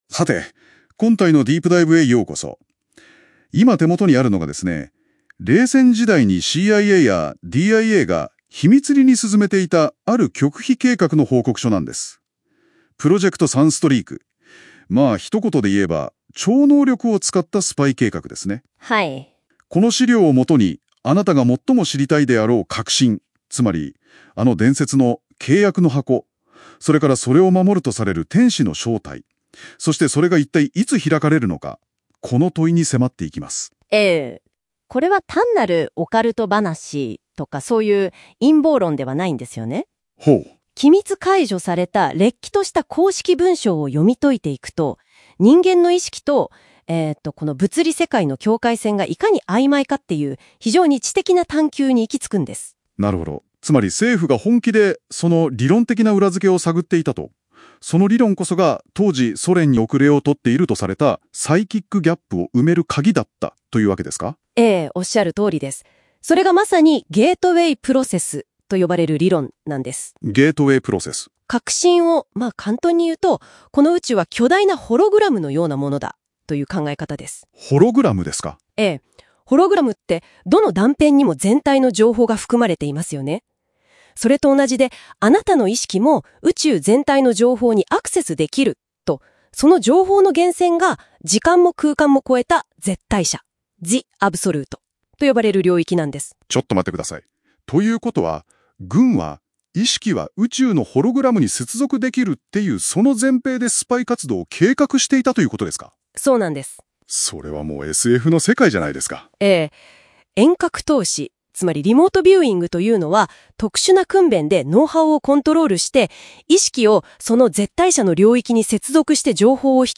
音声解説を追加しました。超能力を使って契約の箱の中身を覗き見る…という大胆な計画。